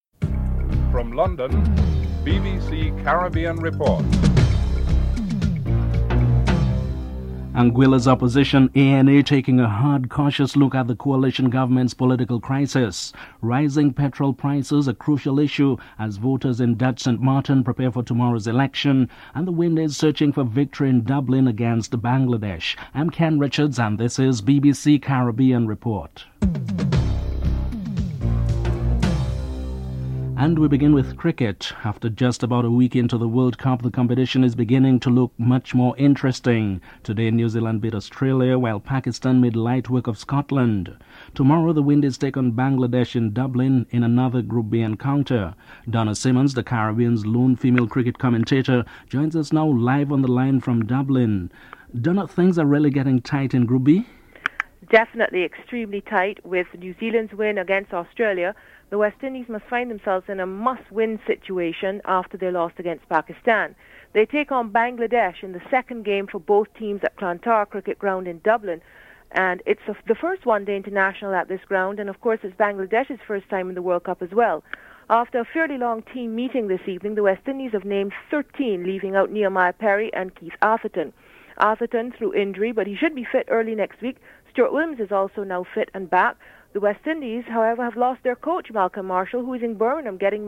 Headlines with anchor